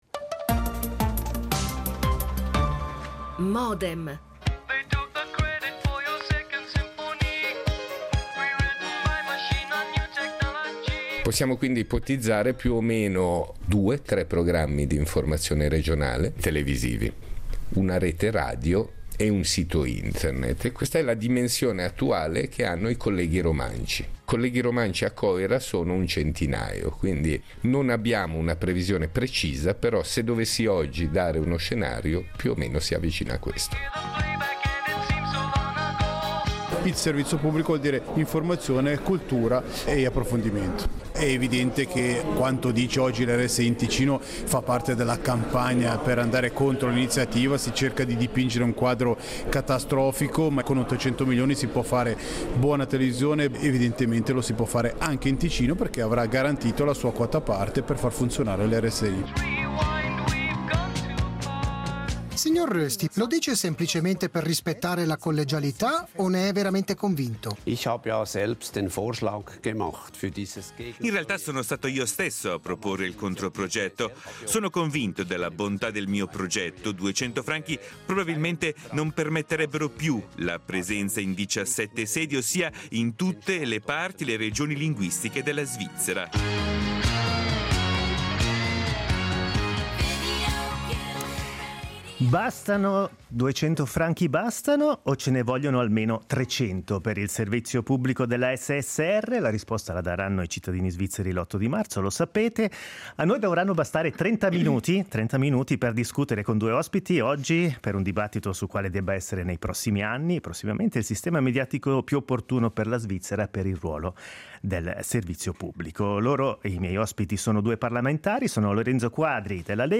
La campagna sull’iniziativa SSR è agli sgoccioli e l’ultima parola spetterà al popolo l’8 marzo – Dibattito Quadri-Gysin